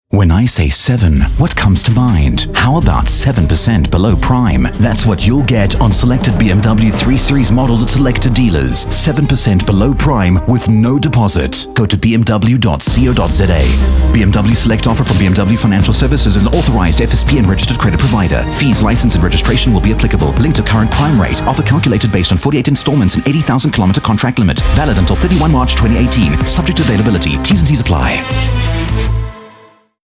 englisch (uk)
europäisches engl
mid-atlantic
Sprechprobe: Werbung (Muttersprache):
BMW 30sec RADIO MIX.mp3